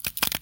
Add fracture sound effects
fracture_4.wav